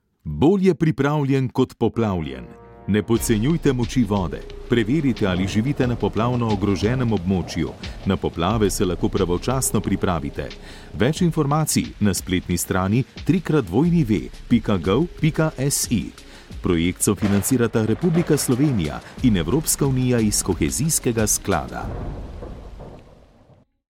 2. Radijski oglas